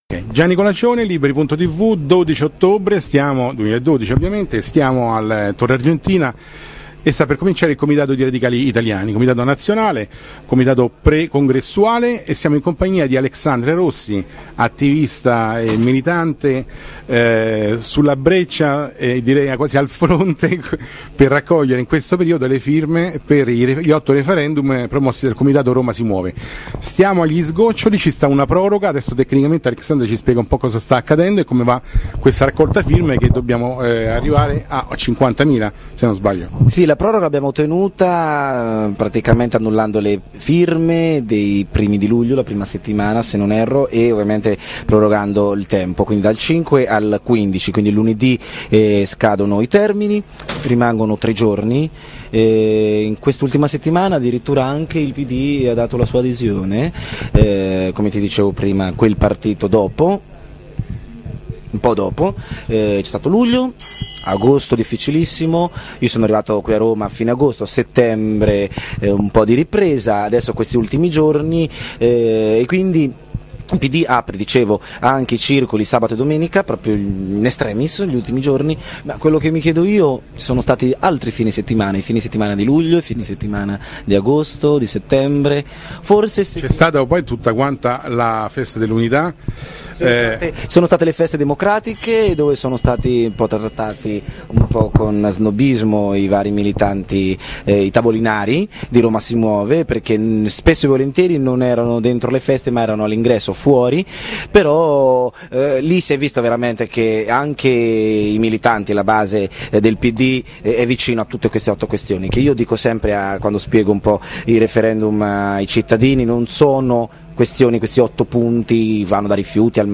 Comitato Nazionale di Radicali italiani 12-13-14 ottobre 2012.